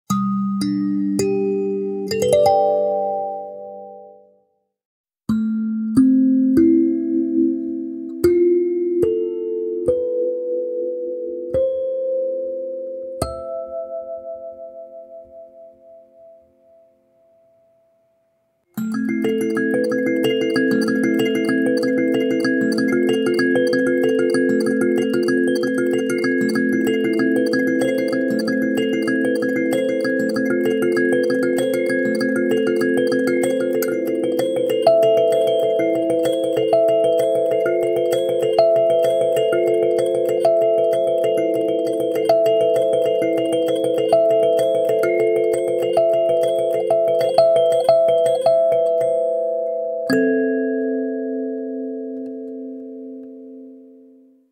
Avec sa peau de veau soigneusement intégrée, cette version haut de gamme de la sansula offre un son encore plus riche et vibrant, amplifié par la membrane naturelle qui lui confère une résonance unique.
Accordée en La Mineur, elle permet de créer des harmonies fluides et apaisantes, idéales pour l’exploration musicale et les pratiques de sonothérapie.
• 9 lamelles accordées en La Mineur, pour une harmonie fluide et intuitive
• Effet sonore en modulant la pression sur la base de l’instrument
Sansula-Deluxe-La-mineur.mp3